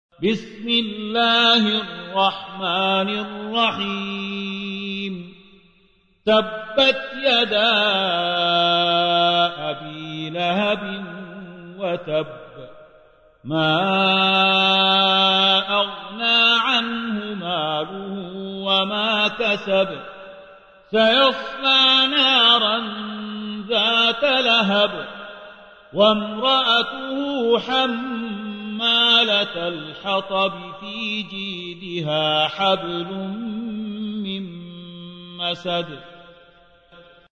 111. سورة المسد / القارئ